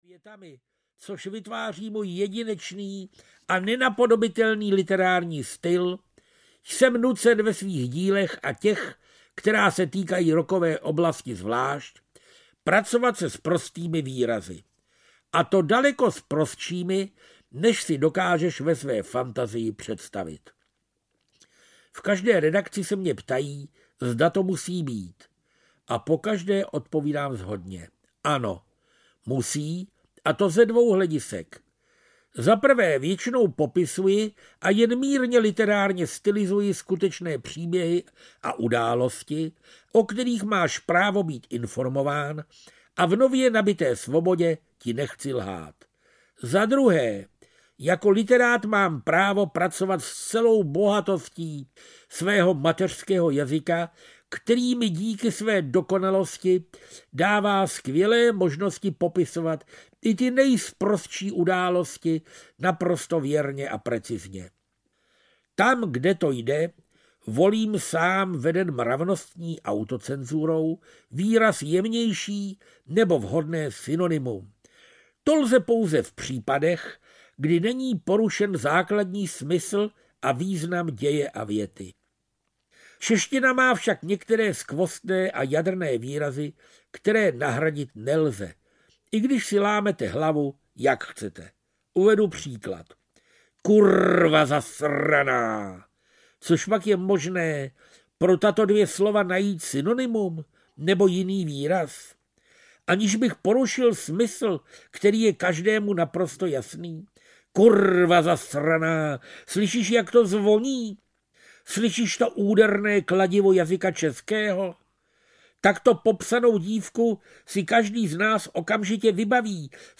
Ruský týden audiokniha
Ukázka z knihy
• InterpretFrantišek Ringo Čech